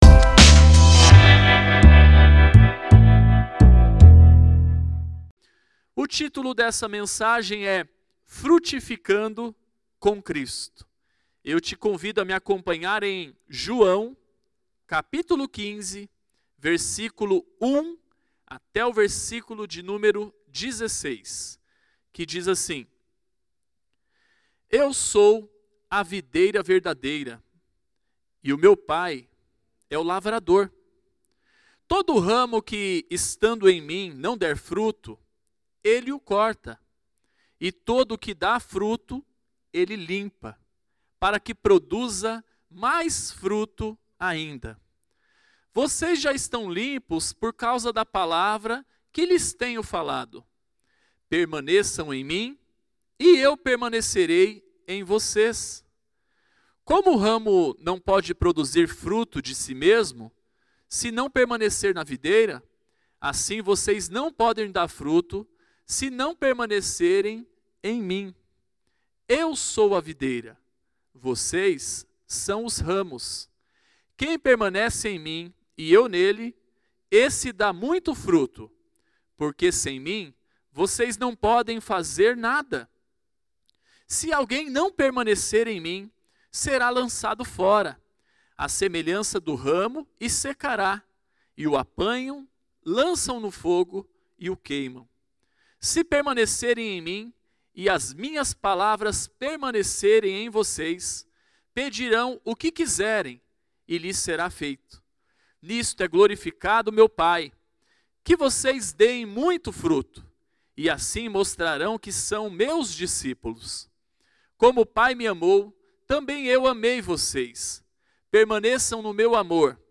Mensagem ministrada